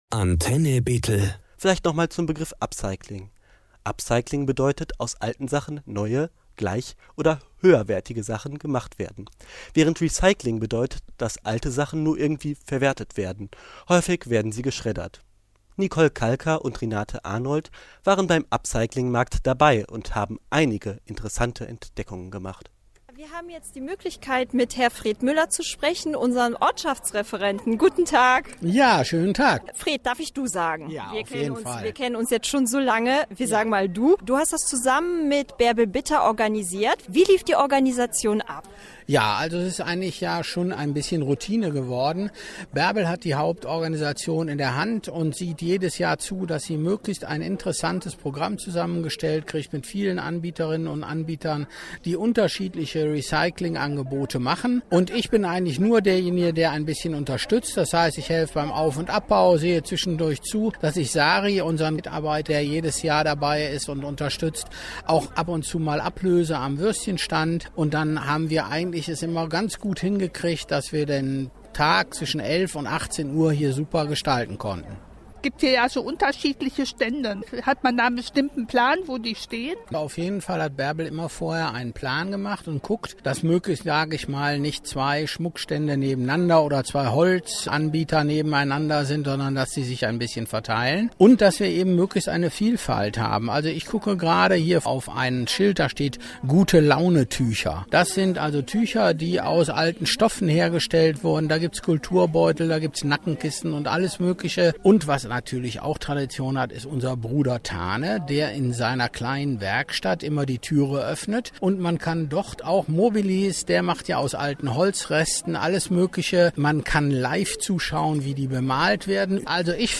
11. Upcycling Markt rund um die Historische Sammlung
Upcycling-Markt-2025.mp3